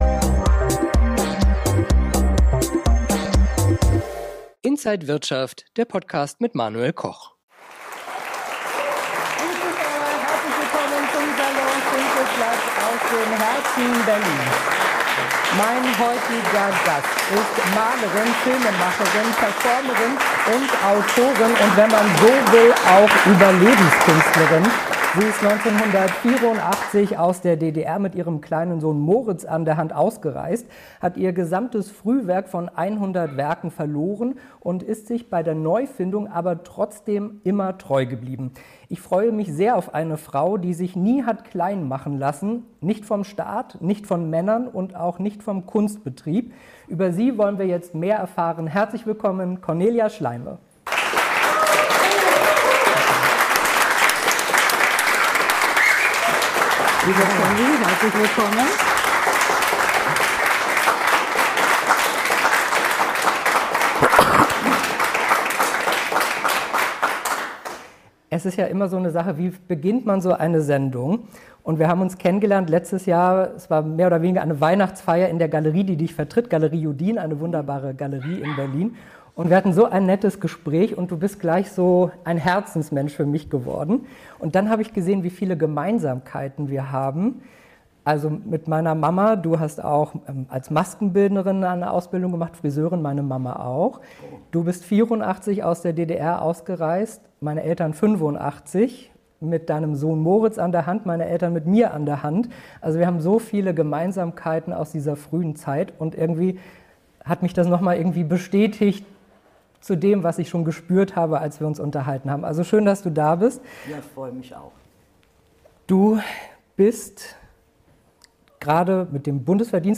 Eine Frau, die sich nie hat kleinmachen lassen — nicht vom DDR-Staat, nicht von Männern und auch nicht vom Kunstbetrieb. Im Salon Schinkelplatz